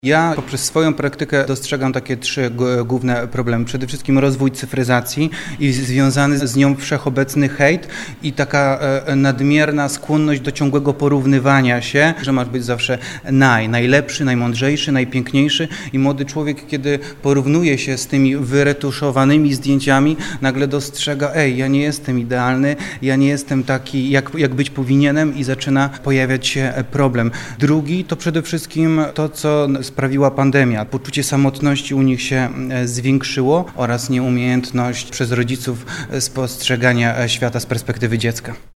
02_psycholog-przyczyny-depresji.mp3